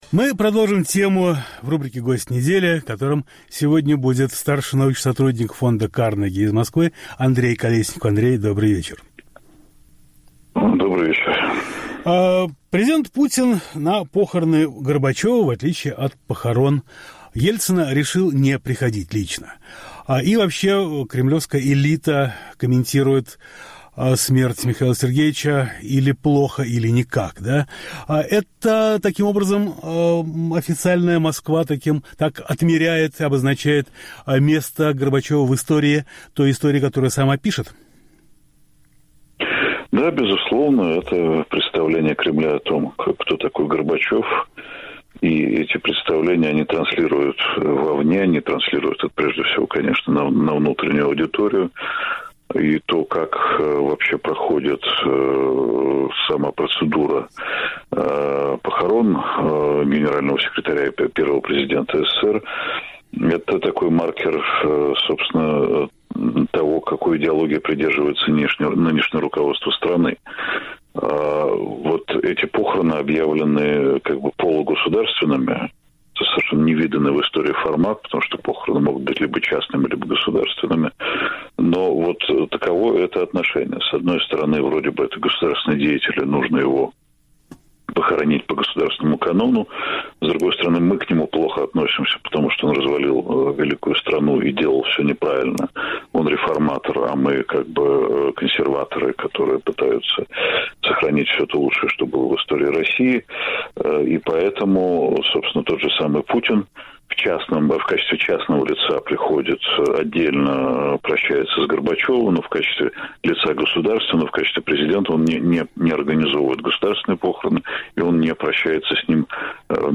Гость недели